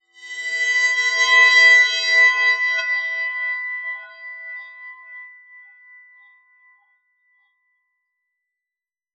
metallic_glimmer_drone_04.wav